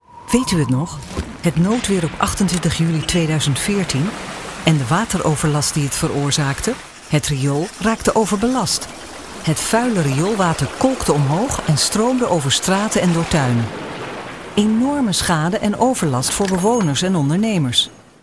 Native speakers